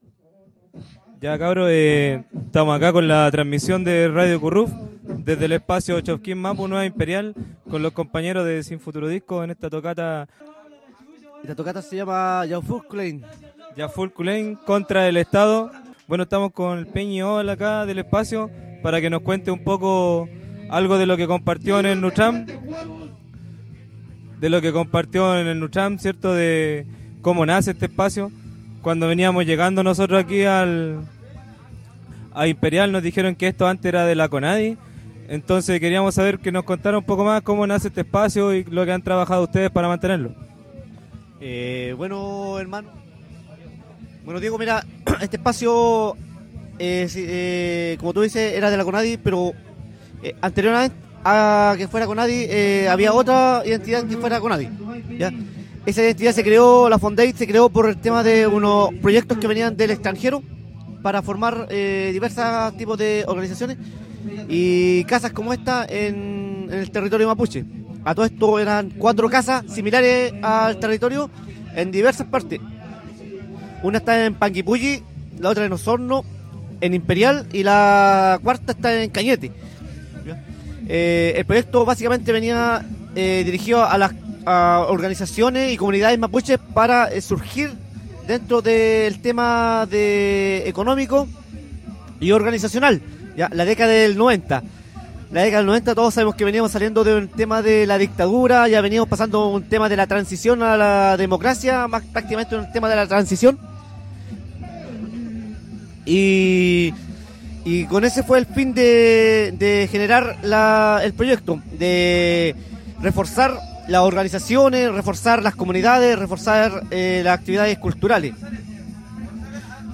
Entrevista-espacio-recuperado-Txokiñ-Mapu-Nueva-Imperial.ogg